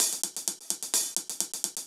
UHH_AcoustiHatA_128-01.wav